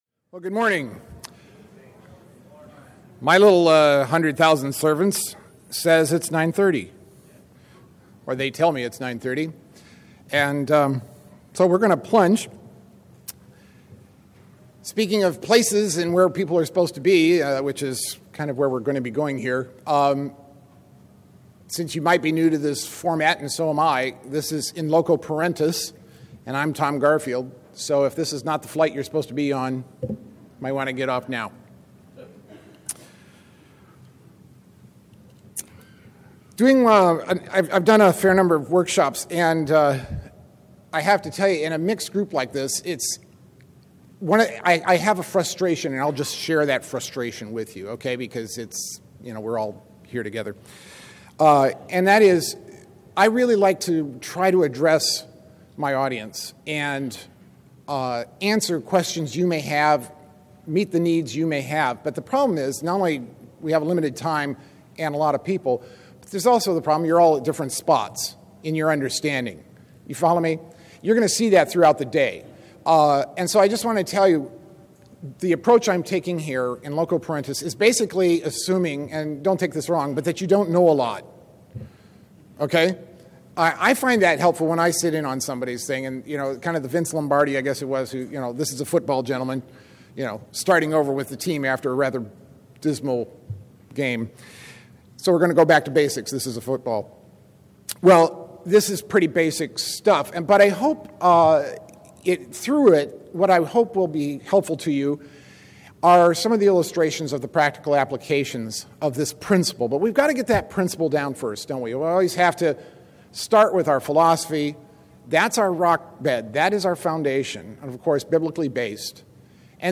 2012 Foundations Talk | 1:03:30 | All Grade Levels, General Classroom